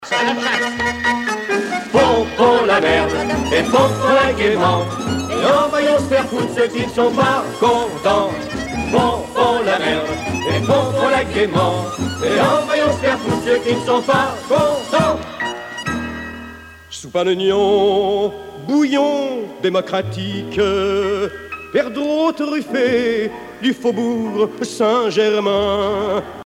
sur un timbre
Pièce musicale éditée